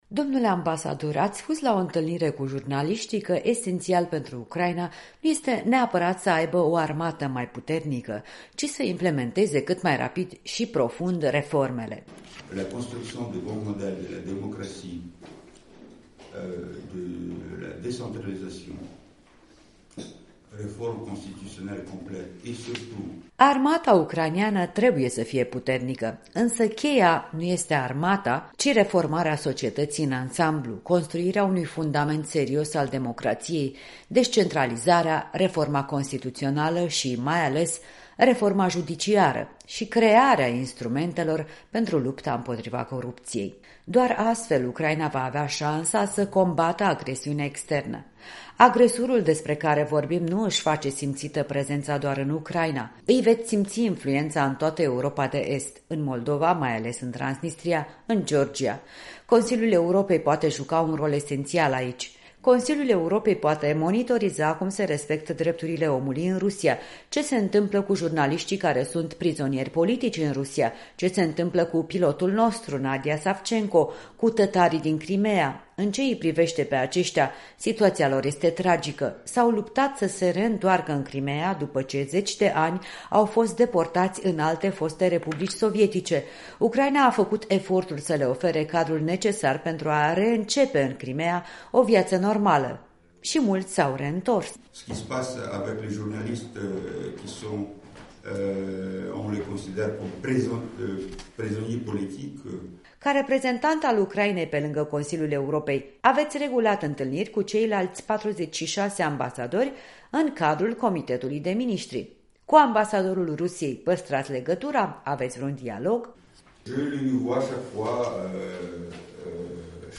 În direct de la Strasbourg cu ambasadorul ucrainean Mykola Tochytskyi